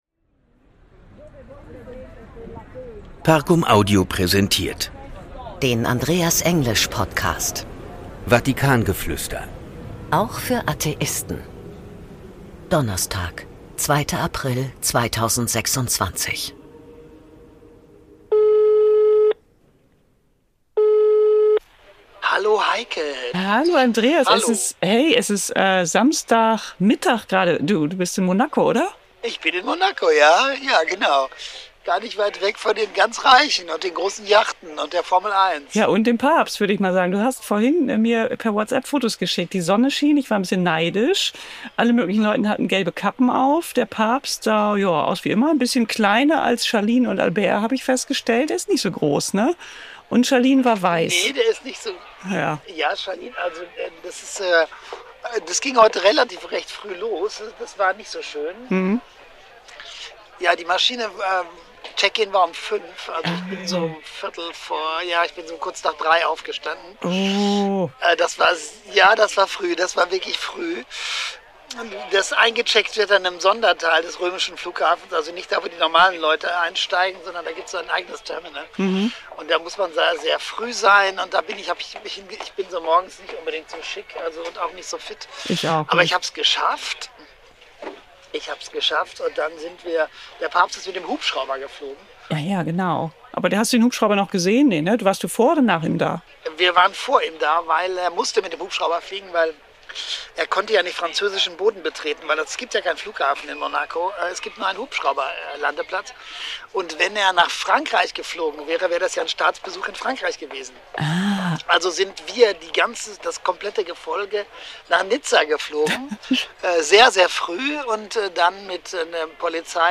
In dieser Folge meldet sich Andreas direkt aus Monte Carlo.